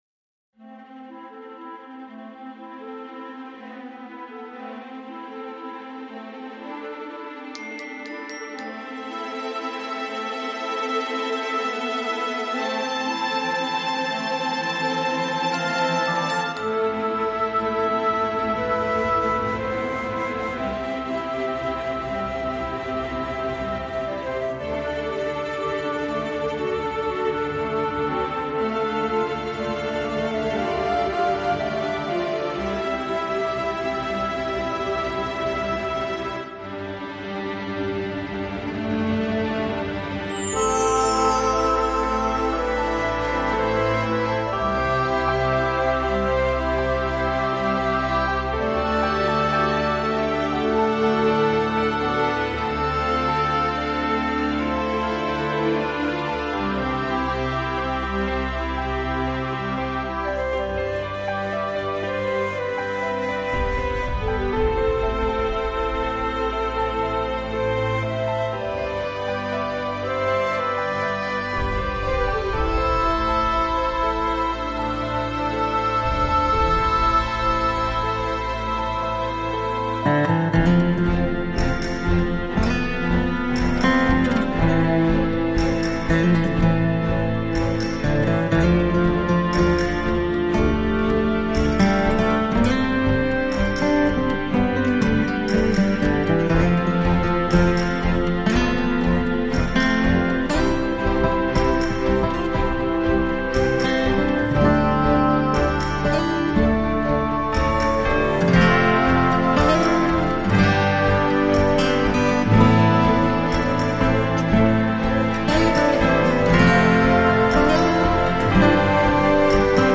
Жанр: New Age